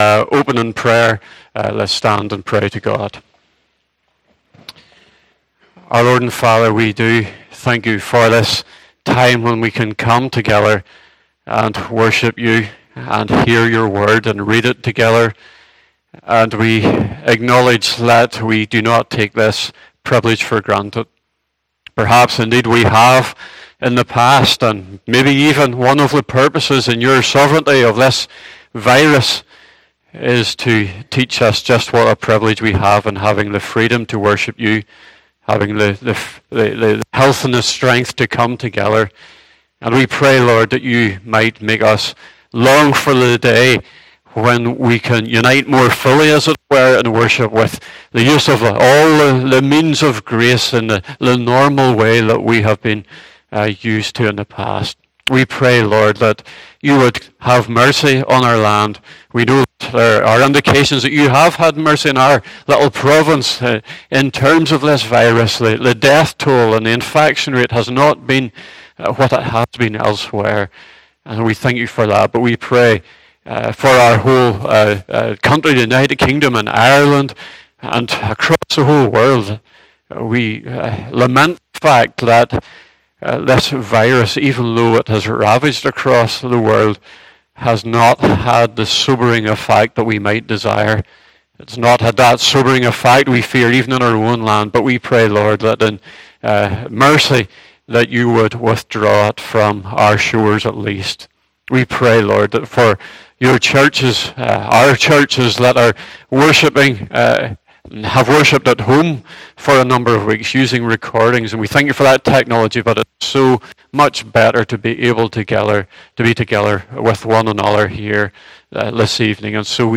Passage: 2 Peter 3 : 1 - 14 Service Type: Evening Service